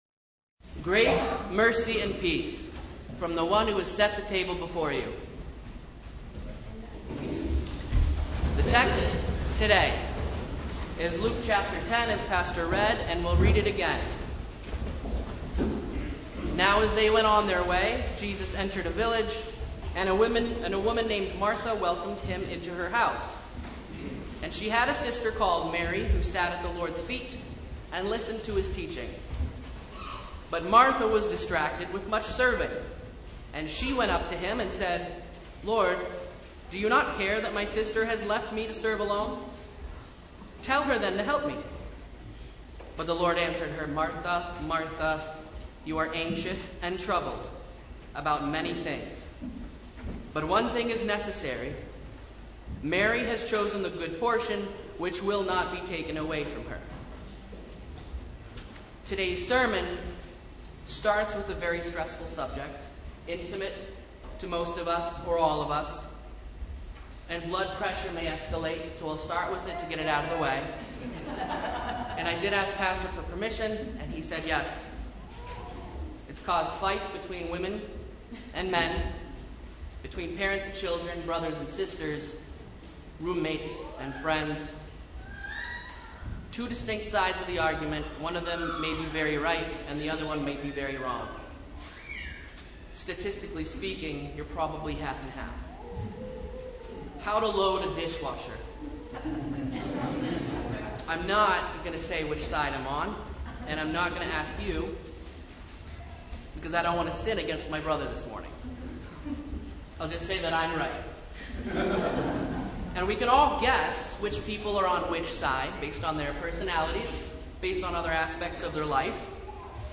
Sermon from Fourth Sunday in Apostles’ Tide (2022)
Sermon Only